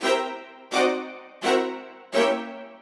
GS_Viols_85-C.wav